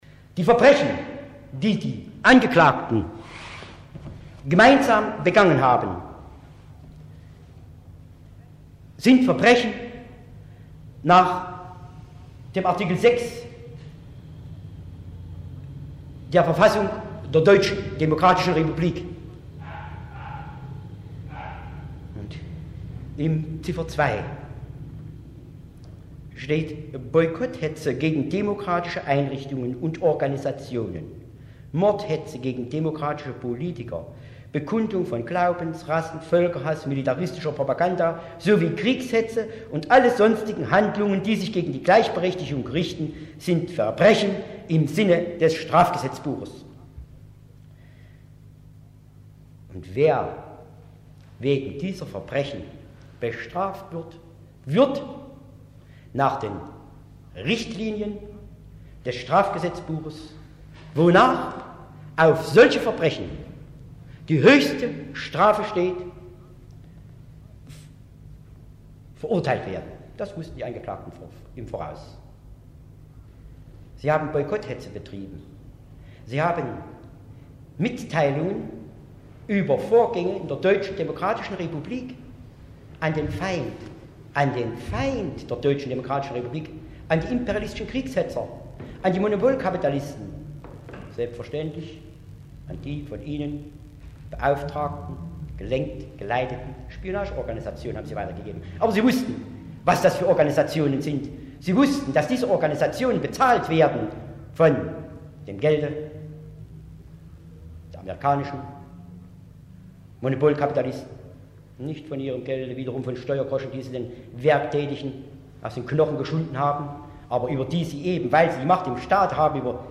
Im Anschluss an die Vernehmung der beiden Angeklagten trug Staatsanwalt Wolfgang Lindner sein Plädoyer vor.
Plädoyer des Staatsanwalts im Geheimprozess gegen Elli Barczatis und Karl Laurenz wegen Spionage